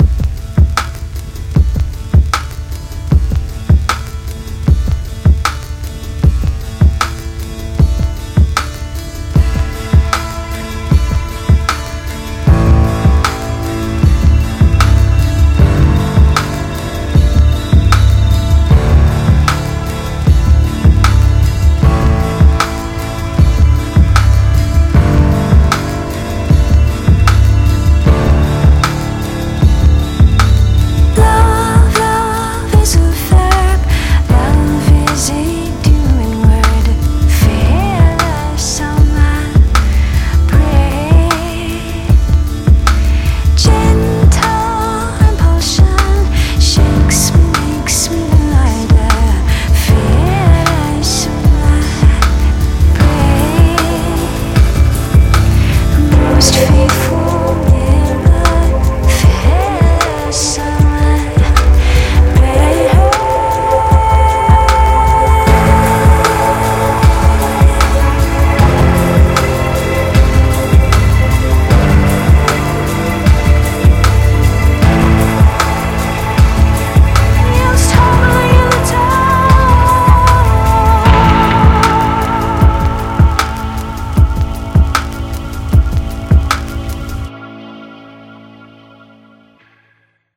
BPM38-77
Audio QualityPerfect (High Quality)